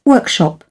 12. workshop (n) /ˈwɜrkˌʃɑp/ hội thảo